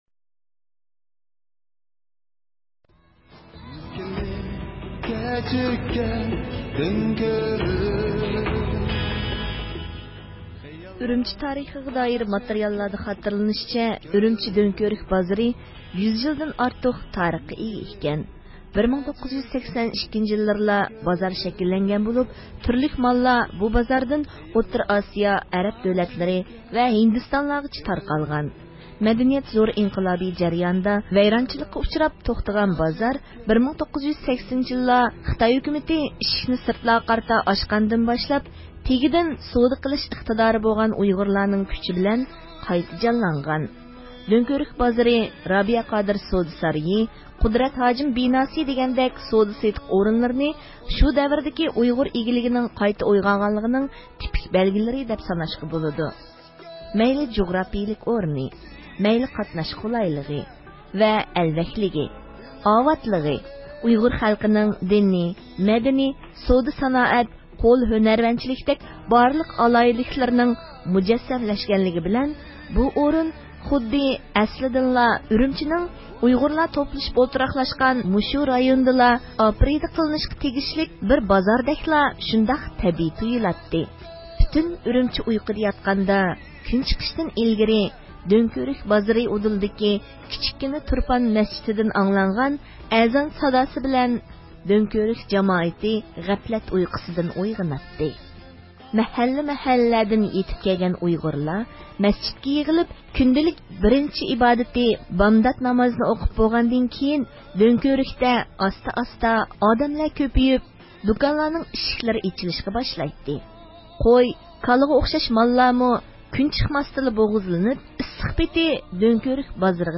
1980 يىللاردا دۆڭكۆۋرۈك بازىرىنىڭ قايتا قۇرۇلۇپ گۈللىنىشىدە تۈرتكىلىك رول ئوينىغان رابىيە قادىر خانىم، دۆڭكۆۋرۈك ھەققىدىكى ئەسلىمىلىرى سوزلەپ ئۆتتىئۇيغۇر مىللى ھەركىتى.